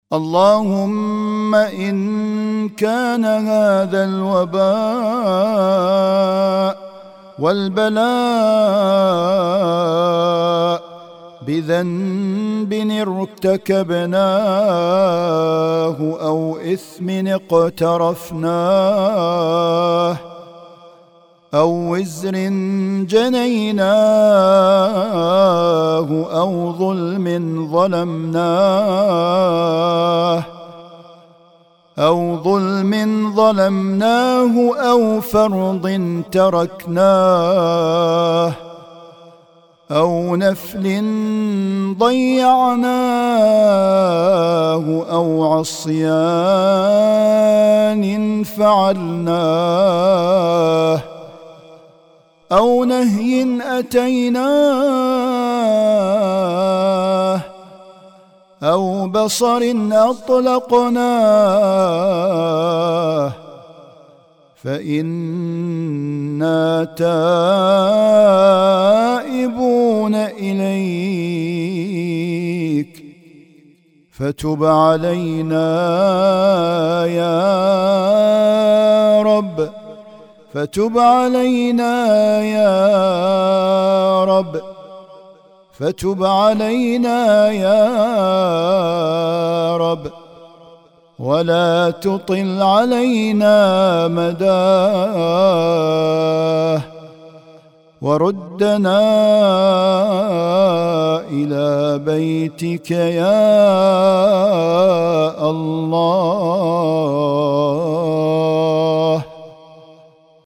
أدعية وأذكار